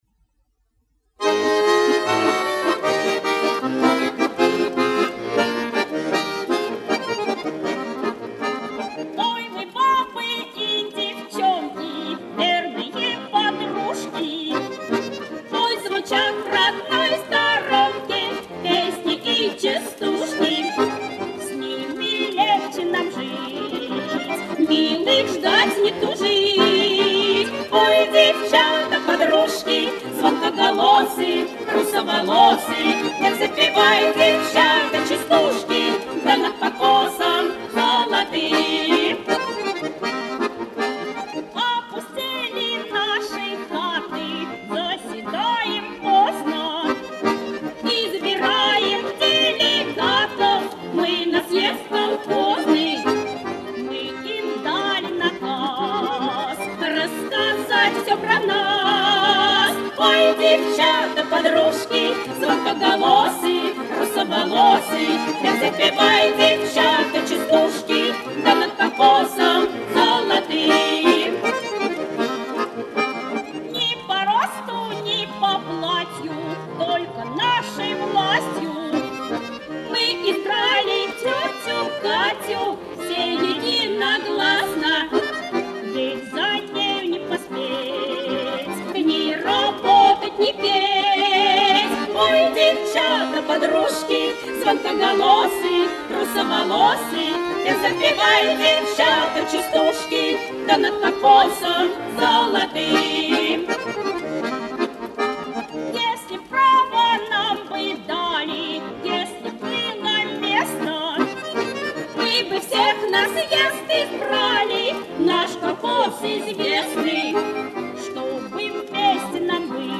Веселая и симпатичная.